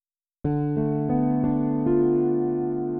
[pkg-mad-maintainers] Bug#465438: Bug#465438: terrible decoding quality with libmad0 on amd64
> There seems to be some 10 KHz signal, but it's like -75 dB.